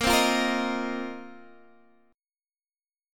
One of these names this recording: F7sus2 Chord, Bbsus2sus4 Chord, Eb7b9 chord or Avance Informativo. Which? Bbsus2sus4 Chord